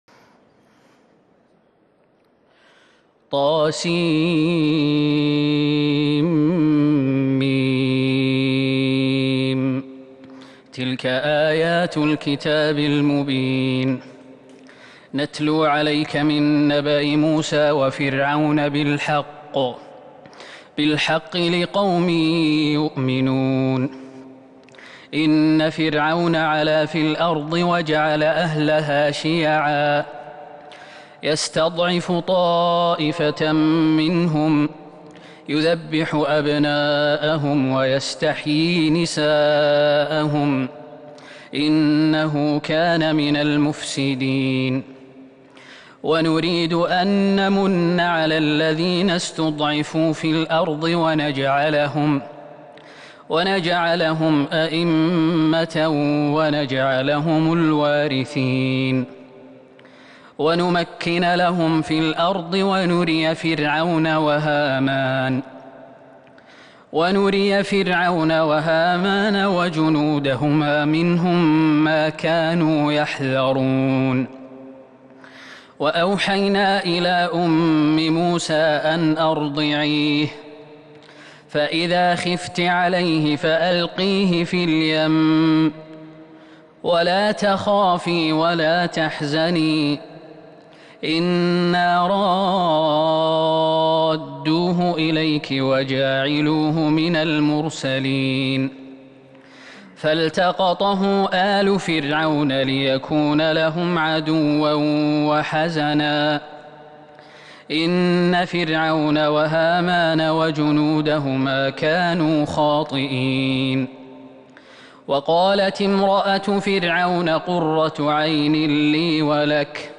تهجد ٢٣ رمضان ١٤٤١هـ من سورة القصص { ١-٥٠ } > تراويح الحرم النبوي عام 1441 🕌 > التراويح - تلاوات الحرمين